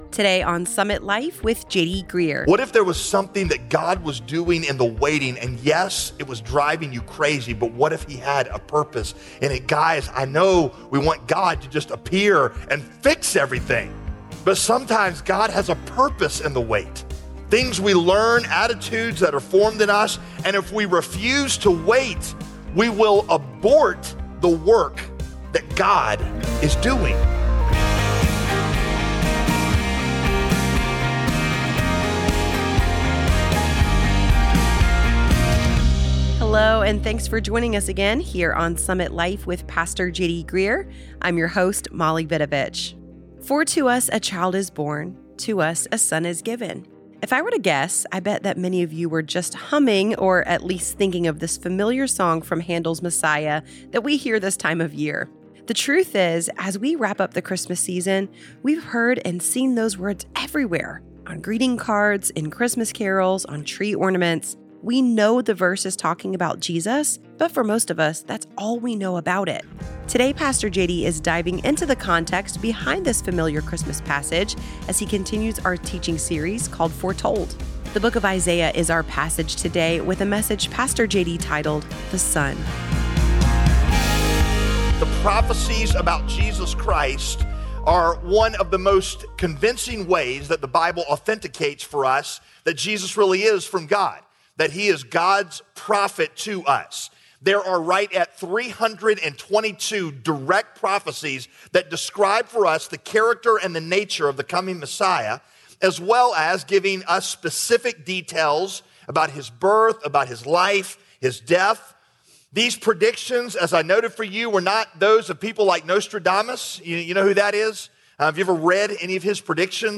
It's a message from our series, Foretold.